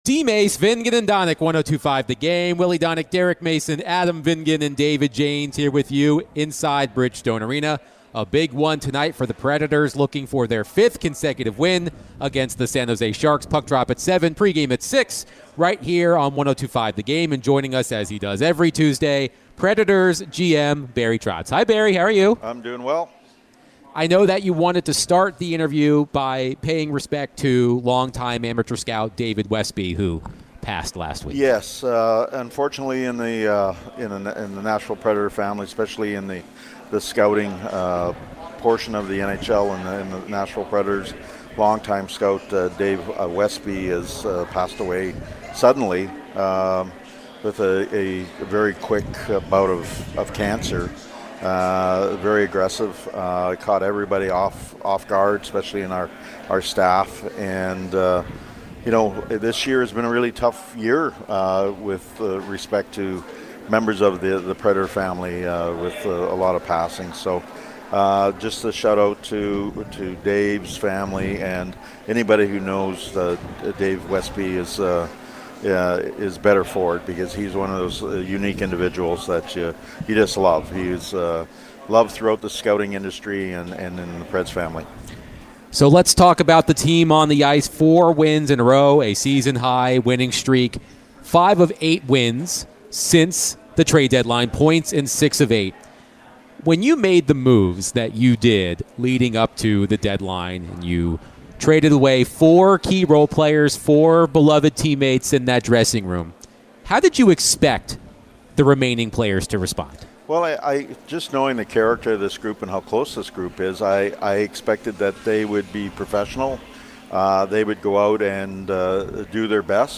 Nashville Predators General Manager Barry Trotz joined DVD for his weekly chat.